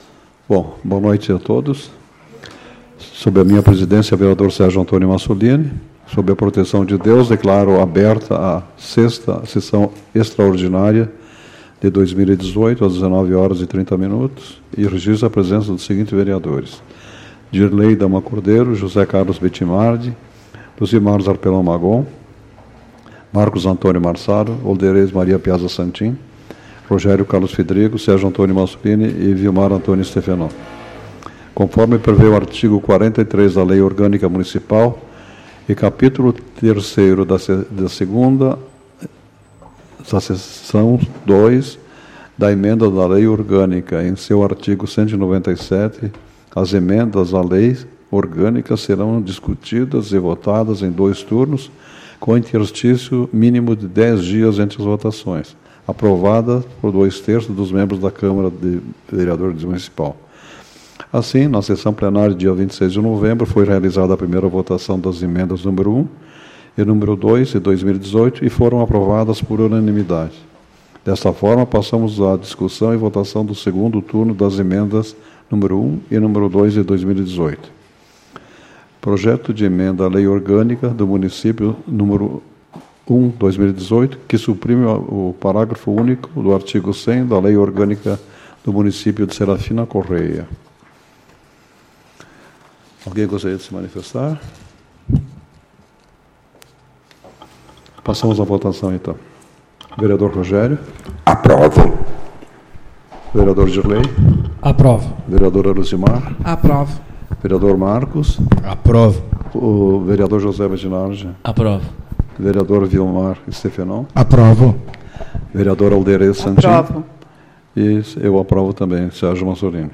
Tipo de Sessão: Extraordinária